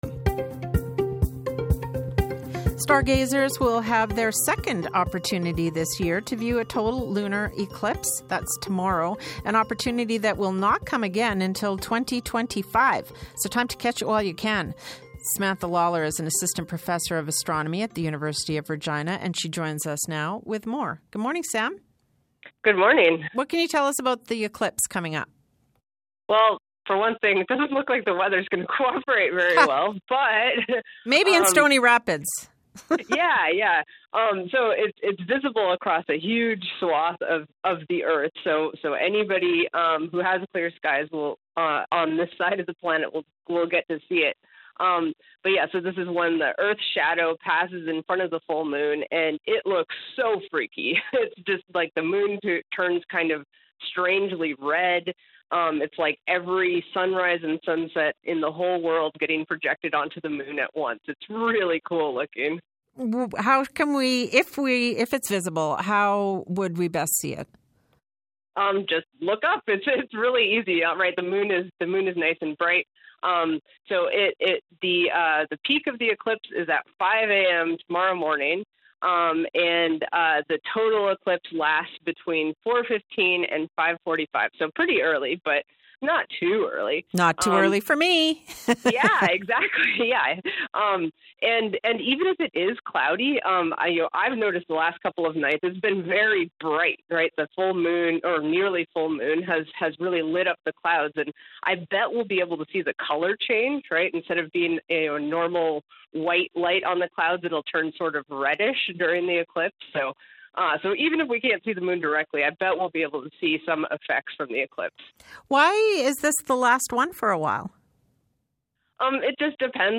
The kids stayed quiet! Interview complete.
I heard exactly 0 kids.